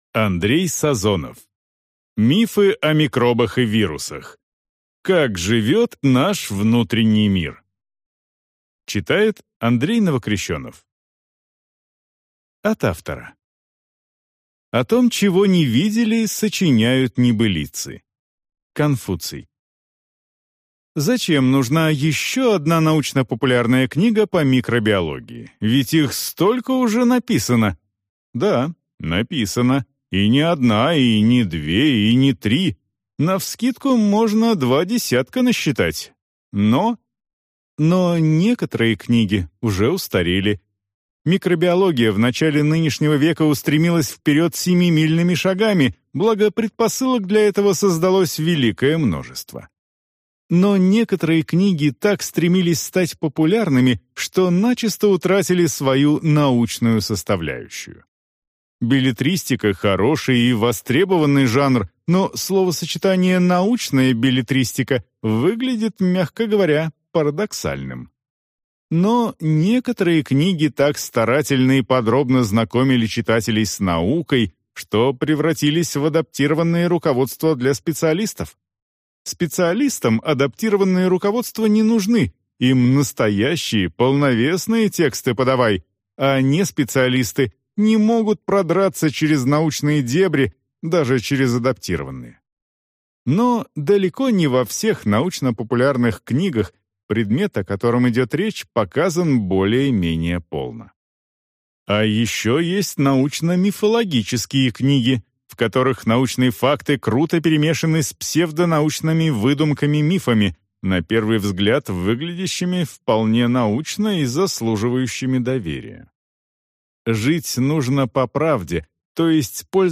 Аудиокнига Мифы о микробах и вирусах. Как живет наш внутренний мир | Библиотека аудиокниг